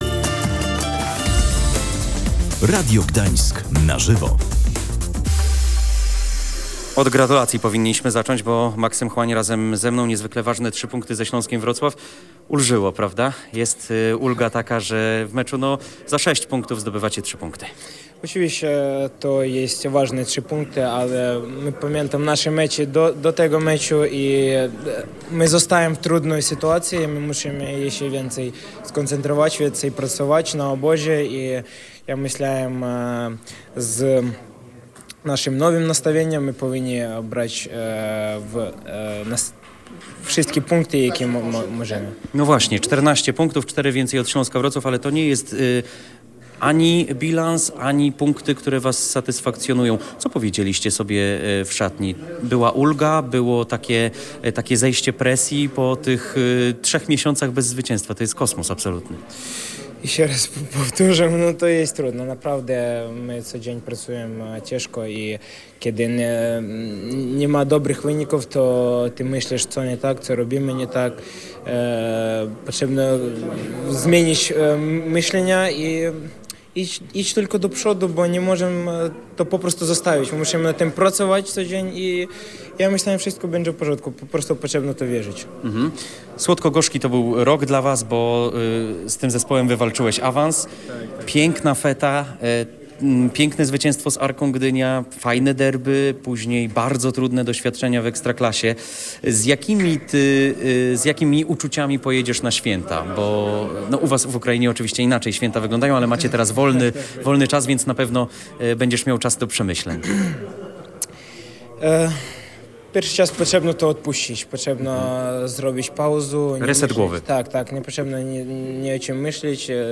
– To były ważne trzy punkty, ale nie zapominamy o naszej sytuacji i dyspozycji z poprzednich meczów. Musimy więcej pracować i skupić się na kolejnych zadaniach na obozie. Z nowym nastawieniem powinniśmy więcej punktować na wiosnę – mówił na antenie Radia Gdańsk po wygranym meczu ze Śląskiem Maksym Chłań.